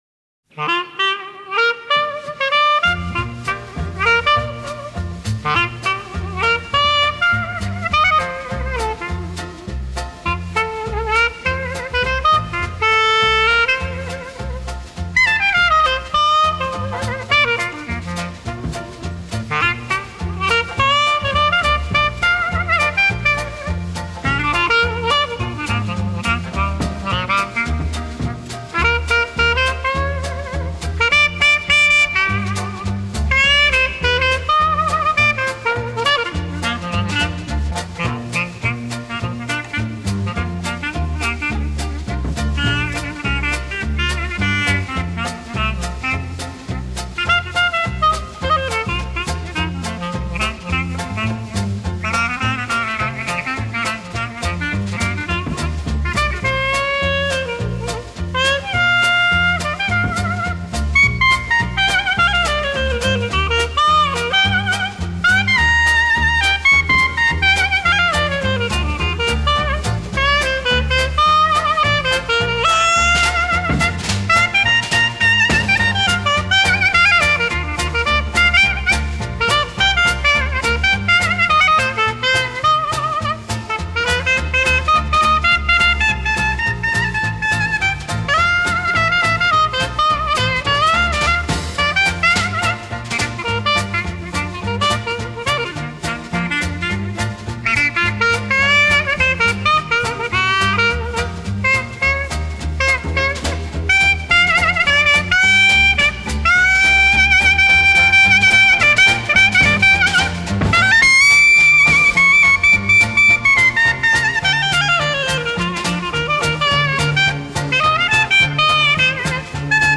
Веселые диксиленды послушаем .
диксиленд